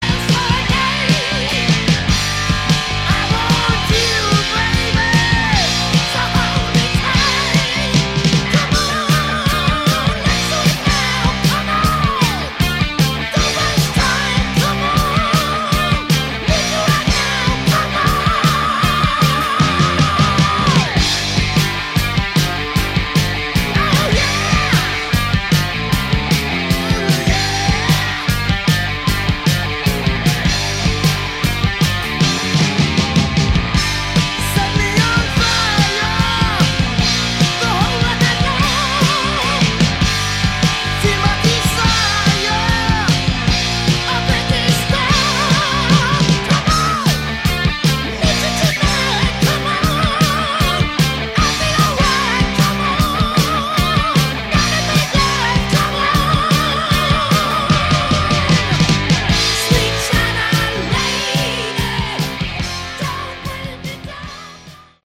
Category: Heavy Metal
lead vocals
guitars
bass guitar
drums